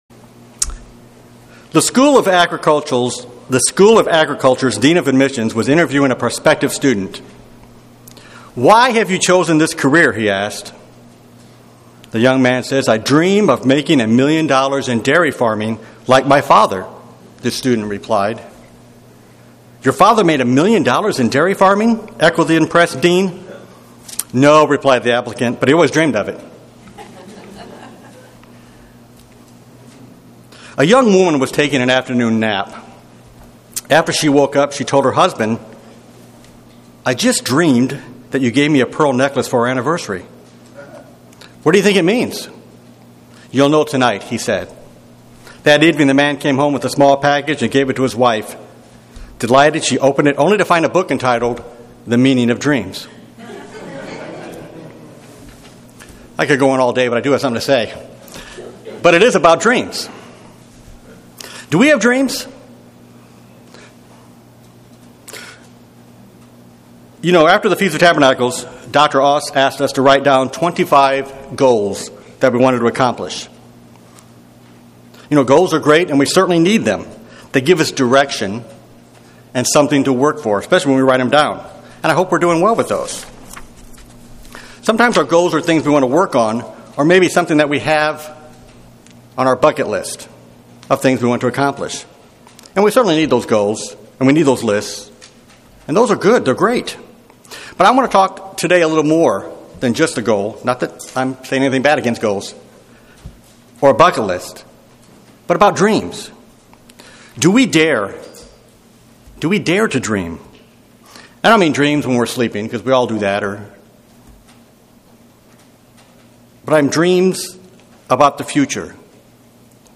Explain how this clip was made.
[Note this sermon was given in Buford on Feb 3, 2018]